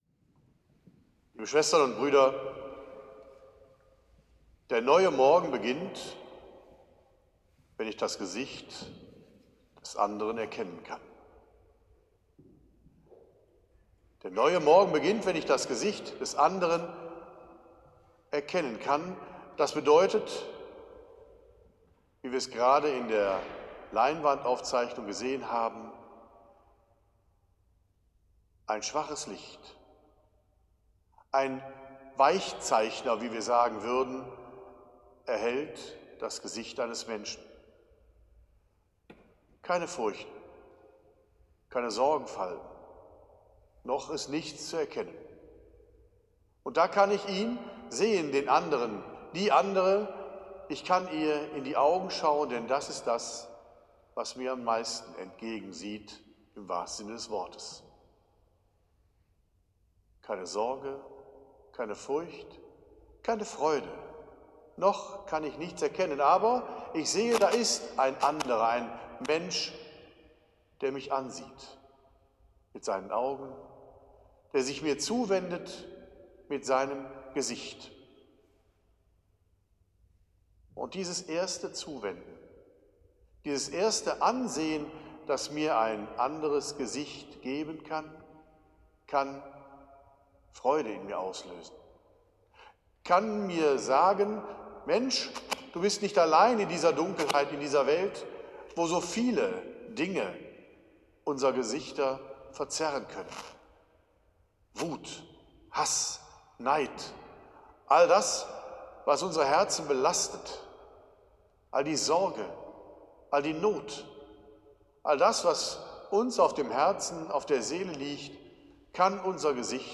Predigt-Christmette-2025.mp3